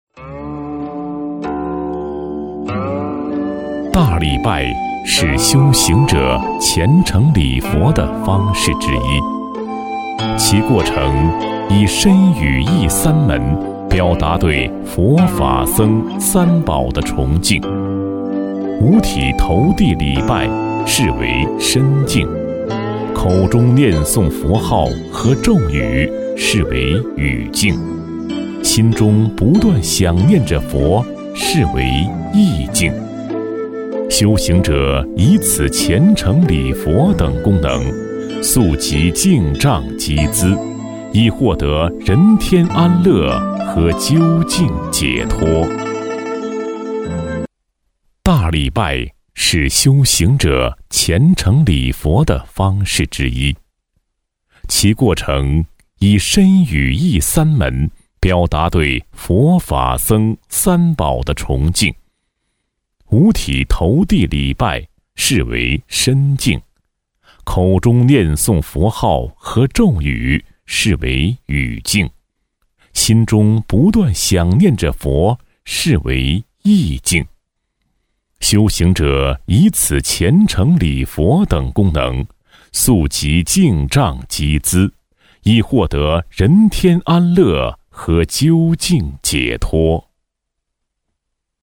纪录片配音-纵声配音网
男22 大礼拜佛教（磁性感情古典）.mp3